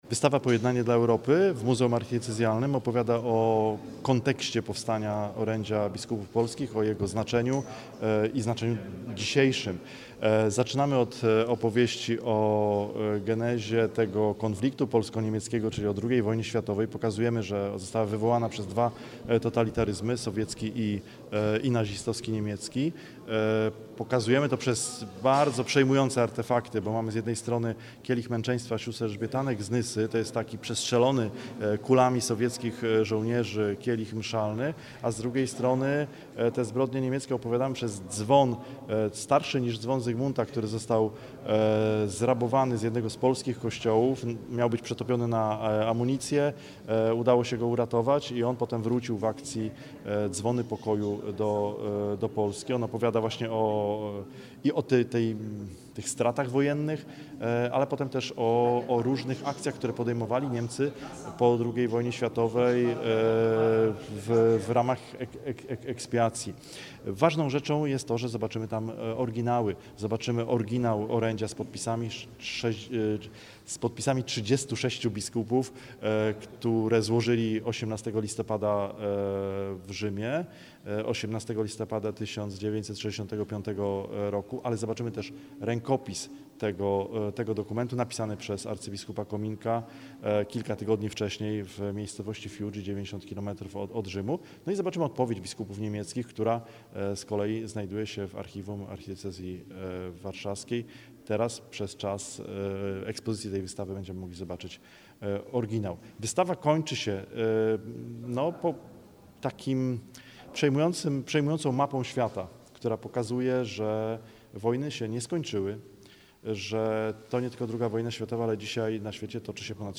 Z okazji 60. rocznicy orędzia biskupów polskich do niemieckich w gmachu Muzeum Archidiecezjalnego we Wrocławiu odbyła się konferencja „Odwaga wyciągniętej ręki”.